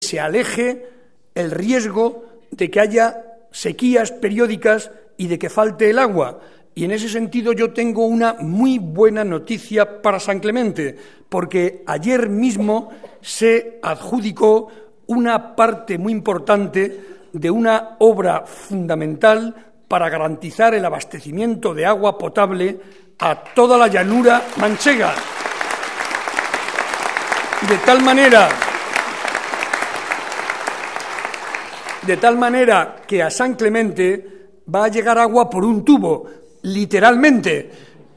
Ante más de 300 vecinos de la localidad, Barreda recordó que, en esta Legislatura, el Gobierno de Castilla-La Mancha ha invertido más de 15 millones de euros en este municipio conquense para mejorar sus instalaciones y prestaciones.